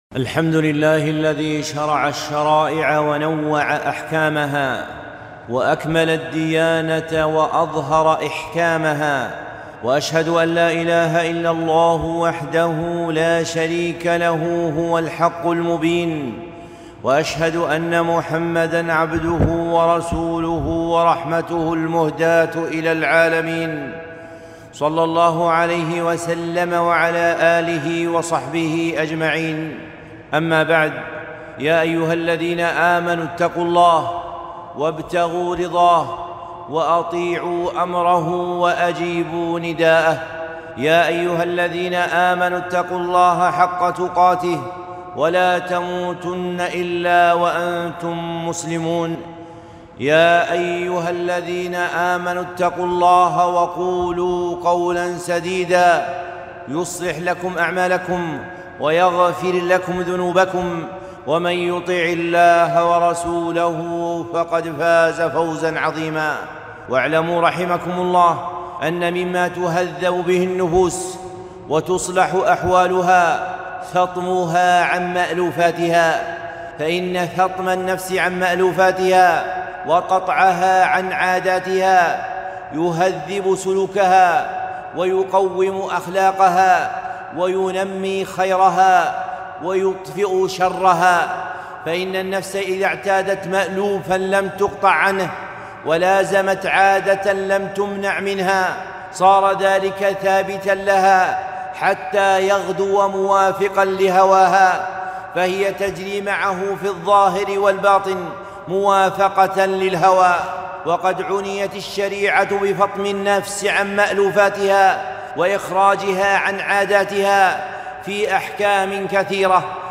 خطبة - فطام الصيام